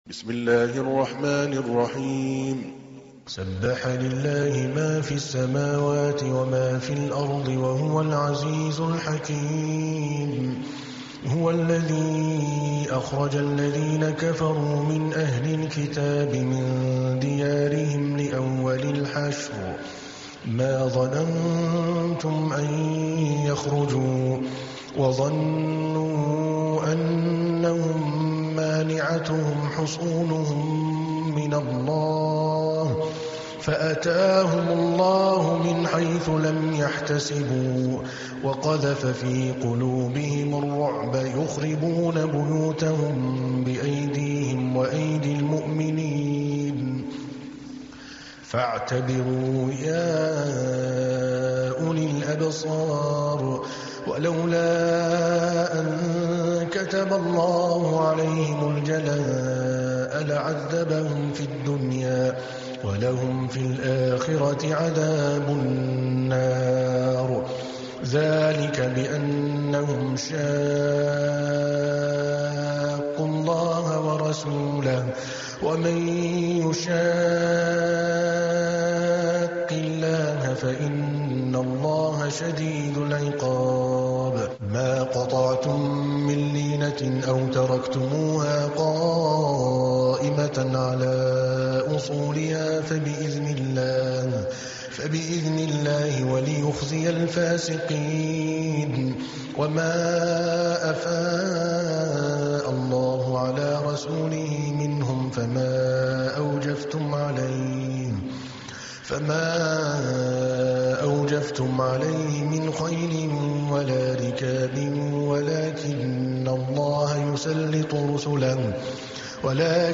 تحميل : 59. سورة الحشر / القارئ عادل الكلباني / القرآن الكريم / موقع يا حسين